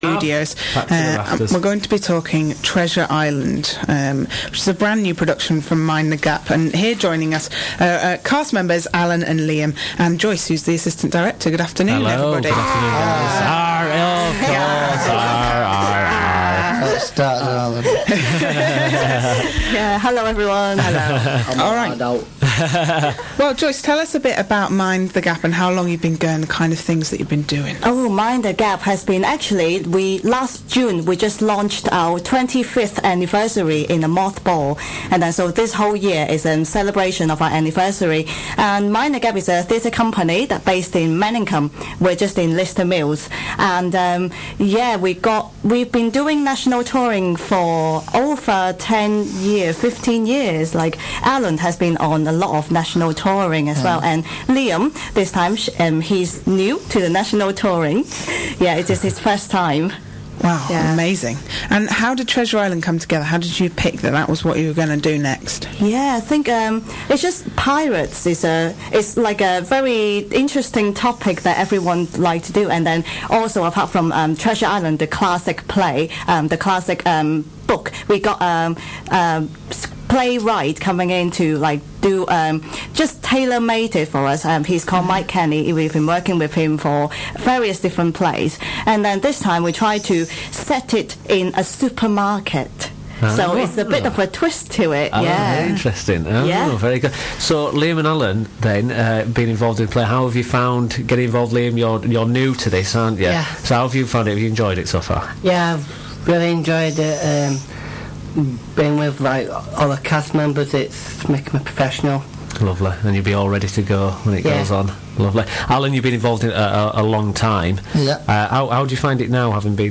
Live on the Radio!